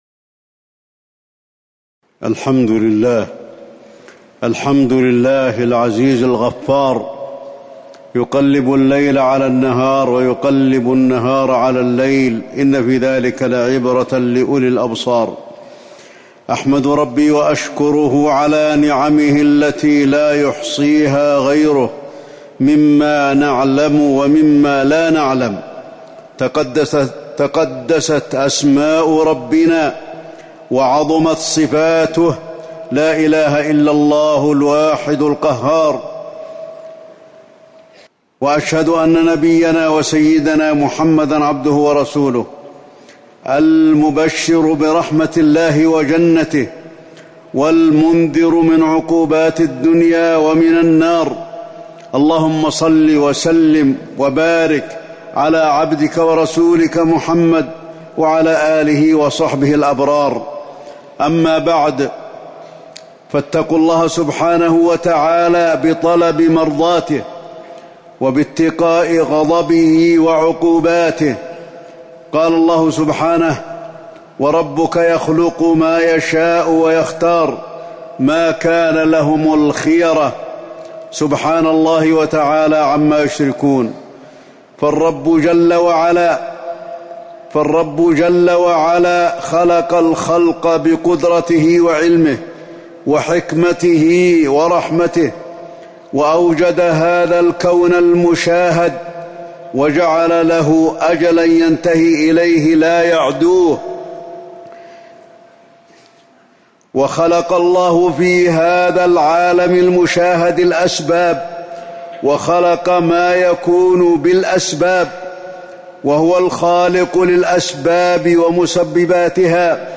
تاريخ النشر ١٤ محرم ١٤٤١ هـ المكان: المسجد النبوي الشيخ: فضيلة الشيخ د. علي بن عبدالرحمن الحذيفي فضيلة الشيخ د. علي بن عبدالرحمن الحذيفي كرم الله ونعمه على عبده